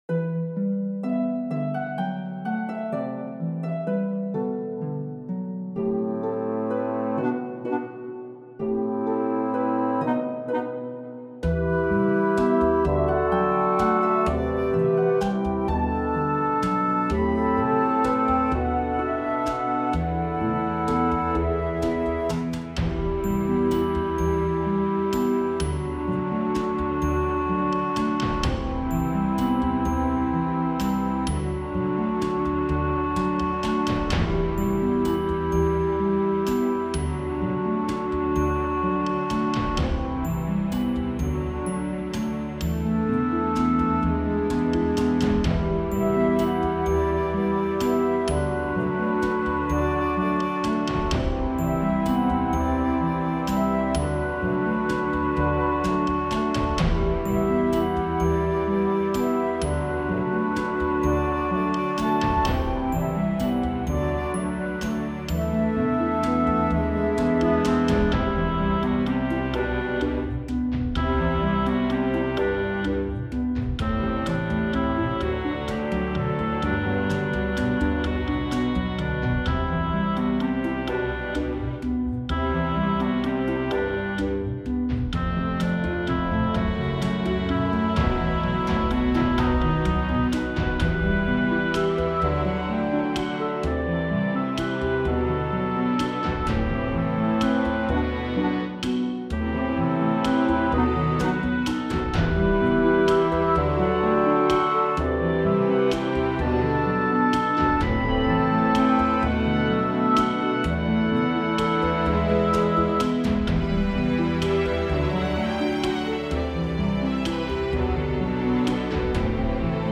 フリーBGM素材- 結構すごい楽器編成の曲。